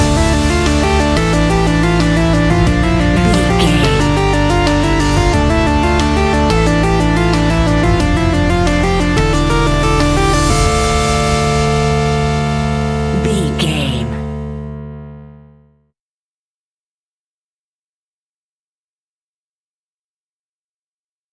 Aeolian/Minor
D
tension
ominous
dark
eerie
drums
synthesiser
ticking
electronic music
electronic instrumentals
Horror Synths